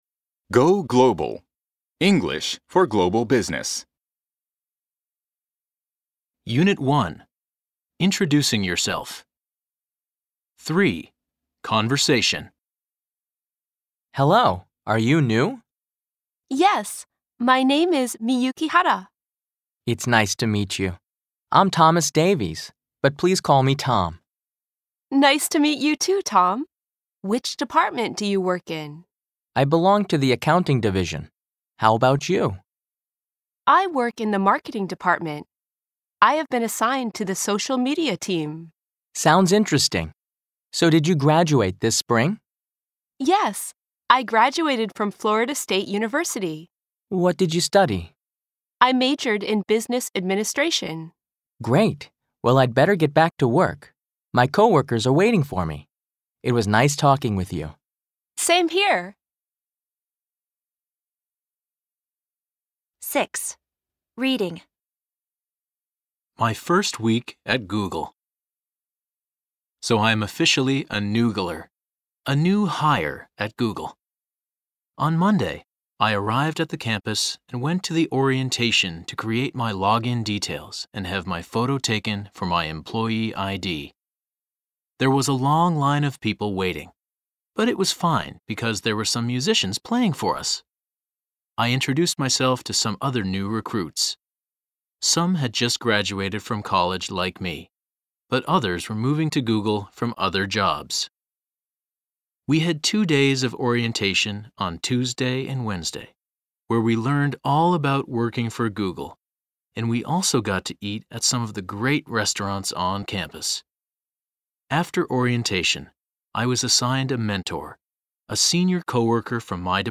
吹き込み Amer E ／ Brit E ／ 他